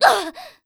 cleric_f_voc_hit_a.wav